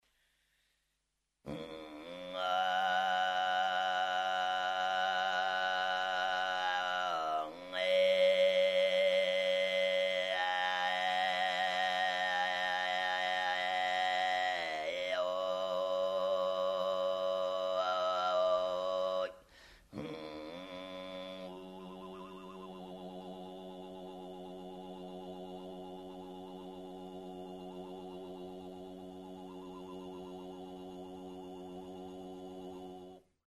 Горловое пение - это особая вокальная техника, производящая два независимых звука одновременно. Основной - низкий бурдонный звук, второй - серия резонирующих флейто-подобных гармоник, намного выше основного звука.
Стили горлового пения